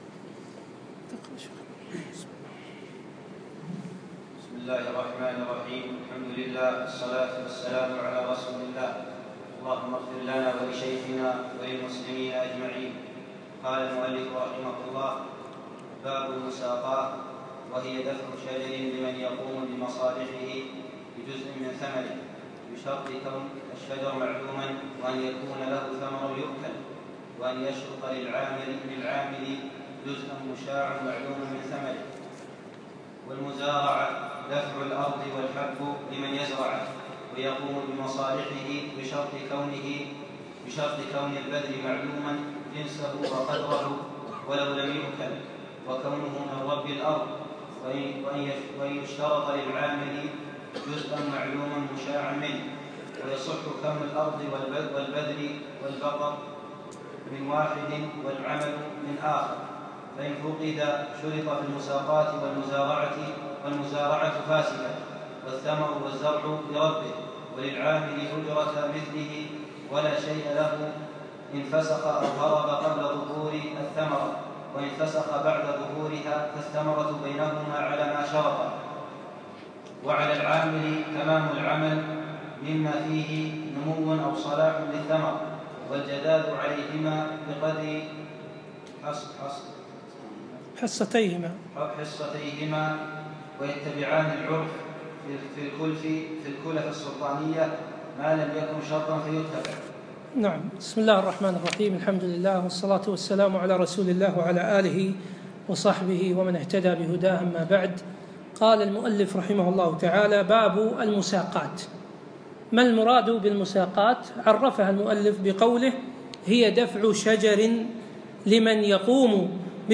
الدرس العاشر